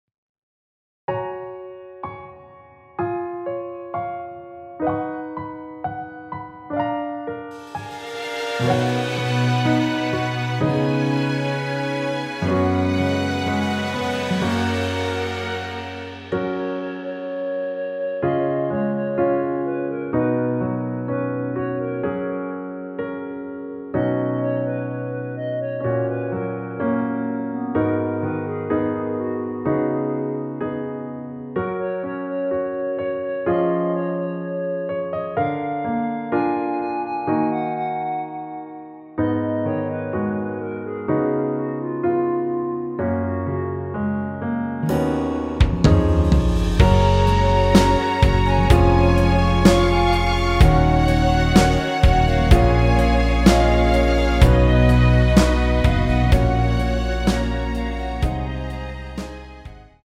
원키에서(+5)올린? (1절앞+후렴)으로 진행되는 멜로디 포함된 MR입니다.(미리듣기 확인)
F#
앞부분30초, 뒷부분30초씩 편집해서 올려 드리고 있습니다.
중간에 음이 끈어지고 다시 나오는 이유는